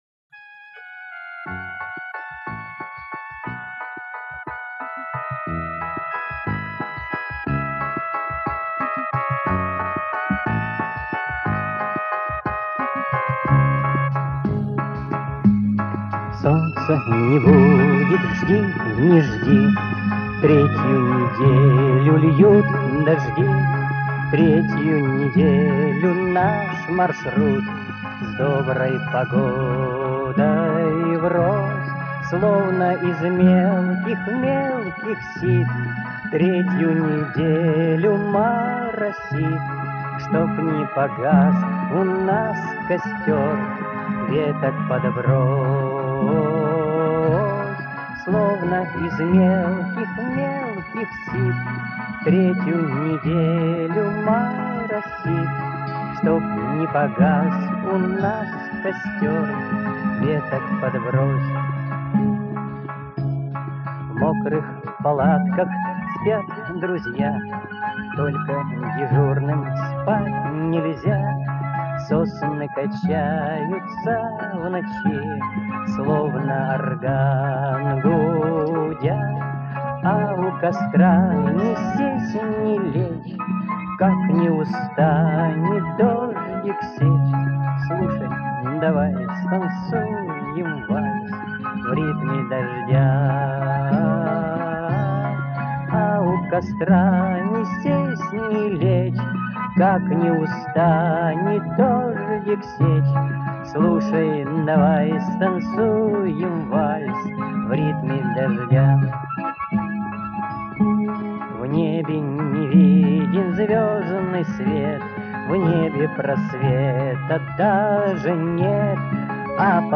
Народный вариант песни